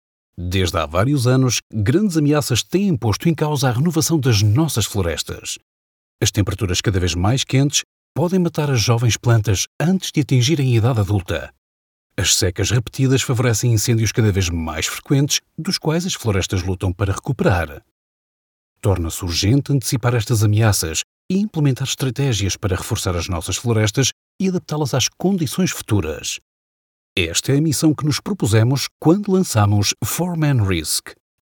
Natural, Travieso, Amable, Cálida, Empresarial
Corporativo